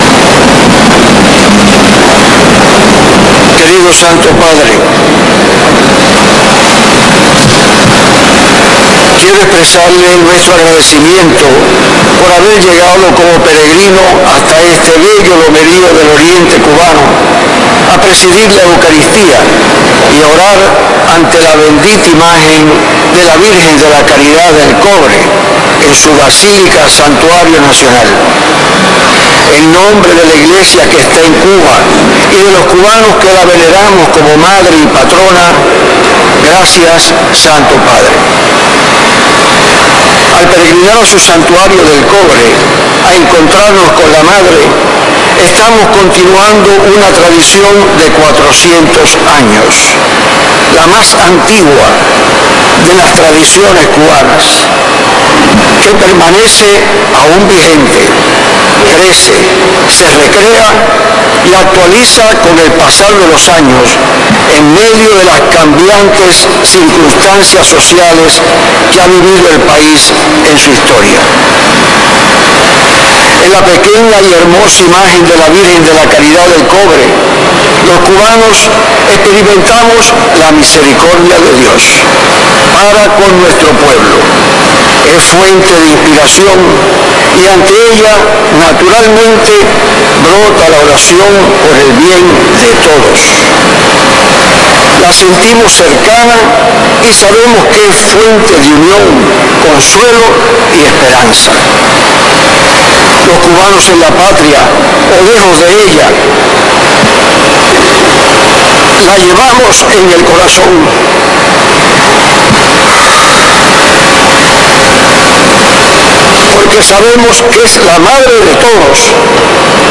Antes de finalizar la misa, última que oficiará Francisco en la mayor de las Antillas, el Arzobispo refirió que el peregrinaje al Santuario del Cobre da continuidad a una de las tradiciones más antigua de Cuba –data de cuatro siglos– que “crece, se recrea  y actualiza con el pasar de los años en medio de las cambiantes circunstancias sociales que ha vivido el país en su historia”, subrayó.
Palabras-del-Monse--or-Dionicio-Grac--a-Ib----ez-Arzobispo-de-Santiago-de-Cuba.mp3